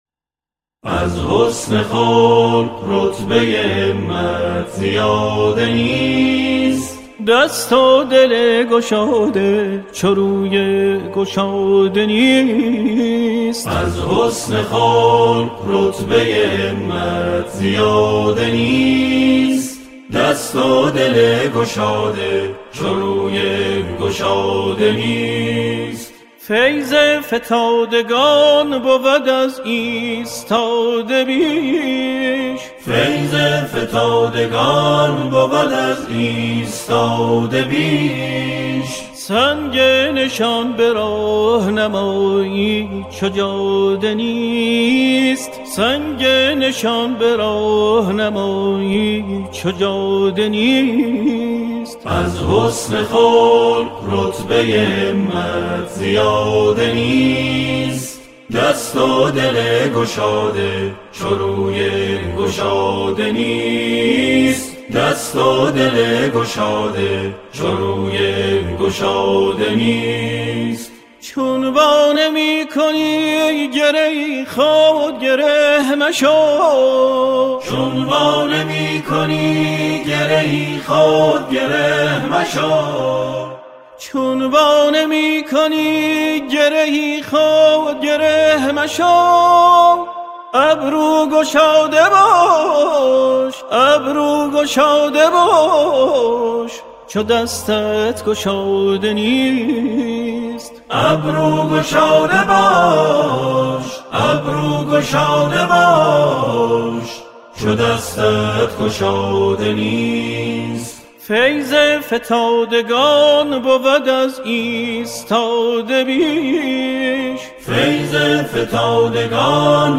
آکاپلا
همراهی گروهی از جمعخوانان اجرا می‌شود.